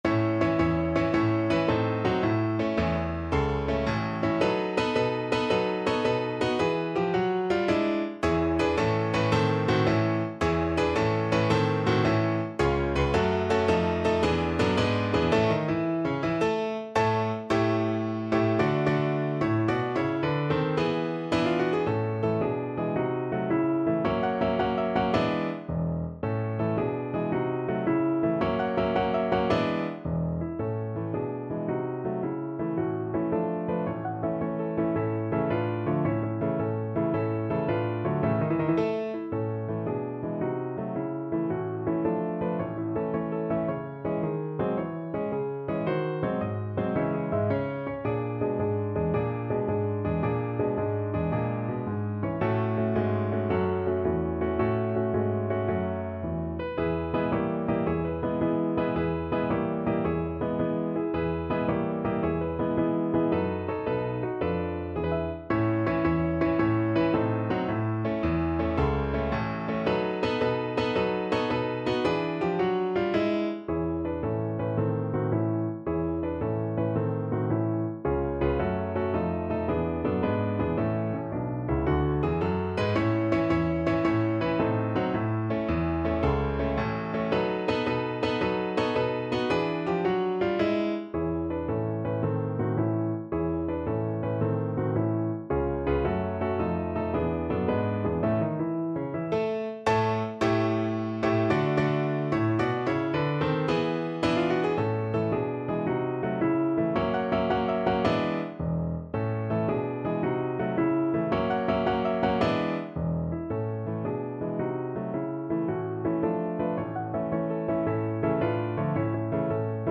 Marziale .=110
6/8 (View more 6/8 Music)
Traditional (View more Traditional Voice Music)